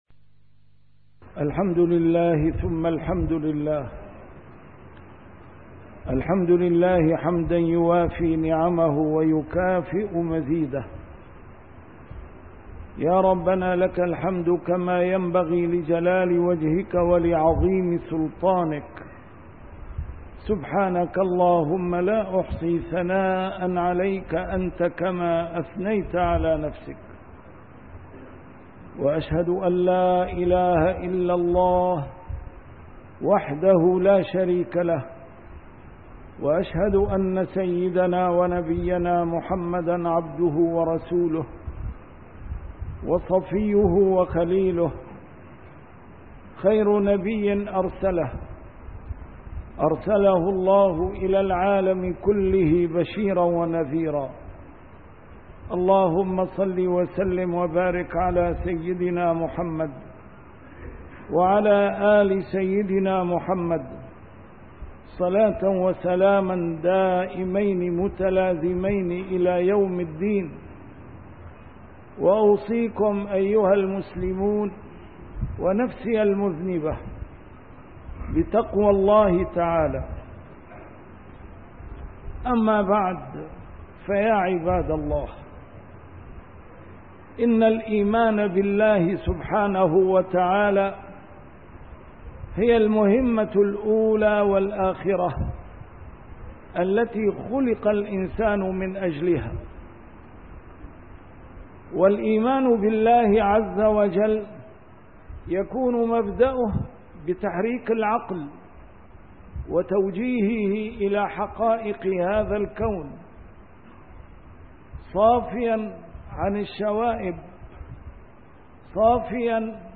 A MARTYR SCHOLAR: IMAM MUHAMMAD SAEED RAMADAN AL-BOUTI - الخطب - غَرسٌ بحاجةٍ إلى سُقيَا